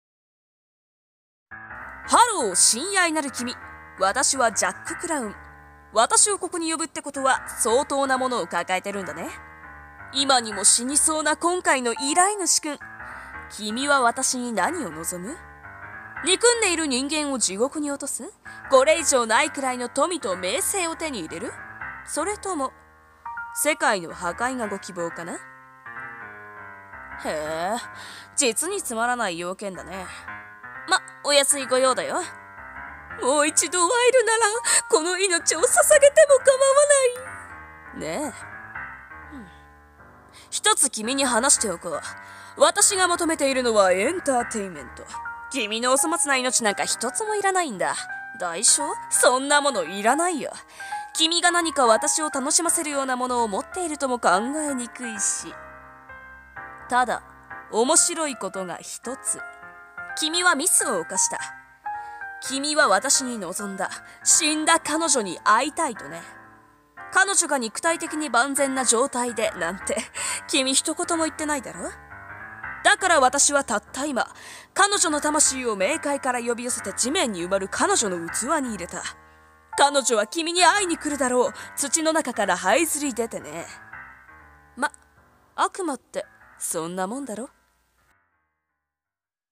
声劇 deviltry